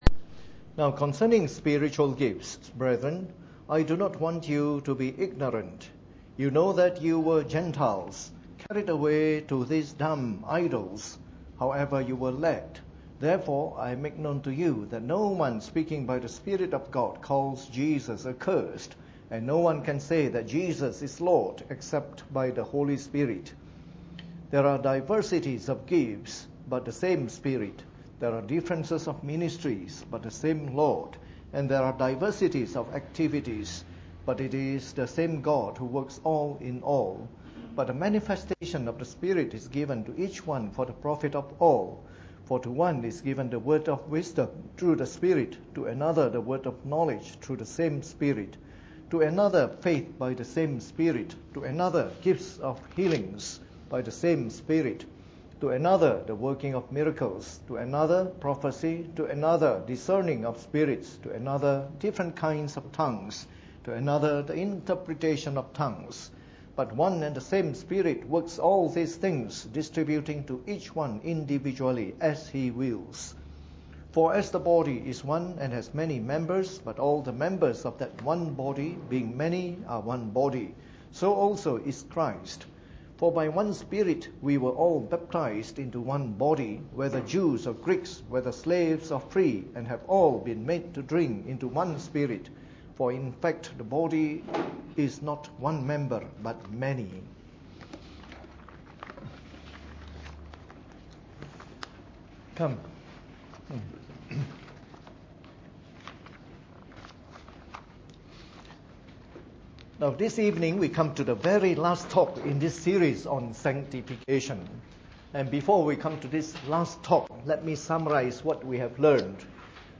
Preached on the 11th of March 2015 during the Bible Study, the final talk from our series on Sanctification.